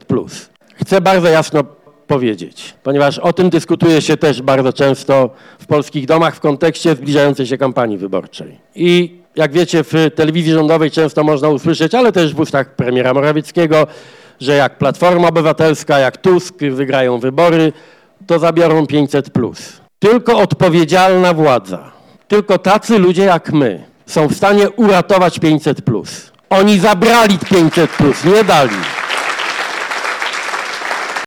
Przewodniczący Platformy Obywatelskiej Donald Tusk podczas swojego pobytu w Szczecinie spotkał się z młodzieżą w ramach „Meet Up Nowa Generacja”.